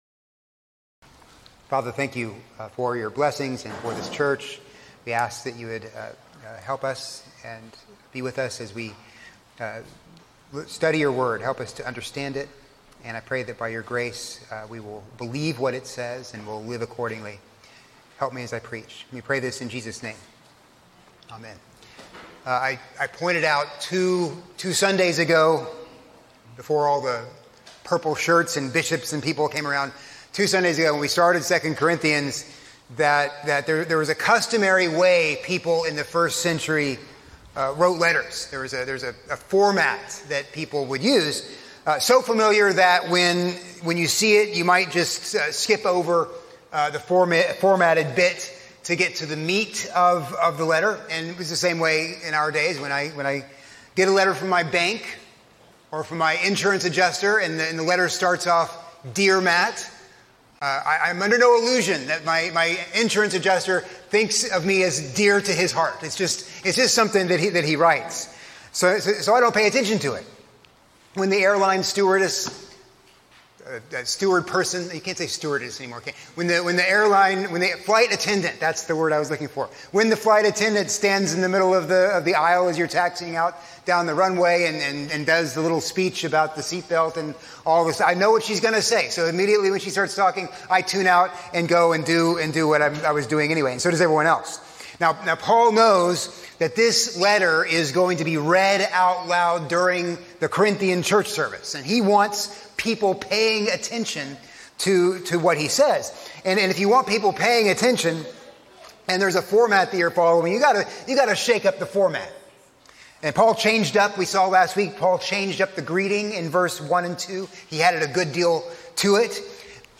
A sermon on II Corinthians 1:3-7